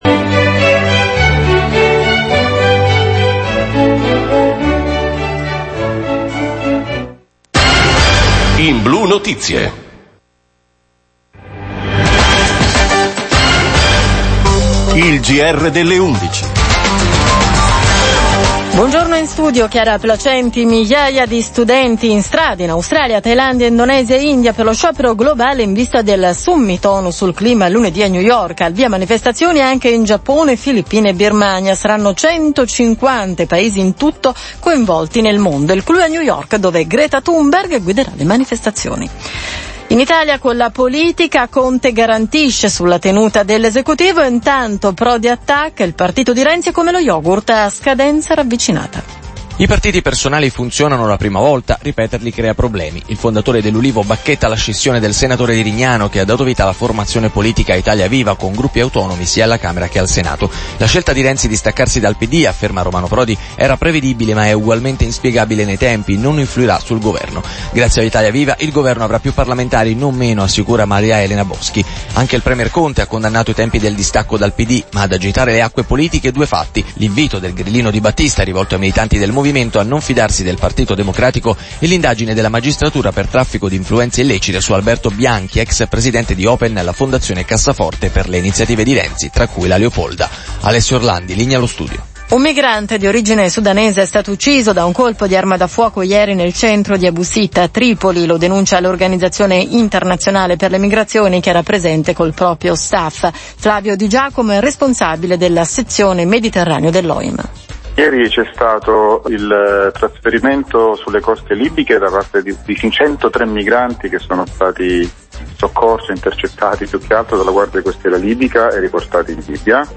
Ecco la registrazione della trasmissione. L’intervista parte dal minuto 05.00: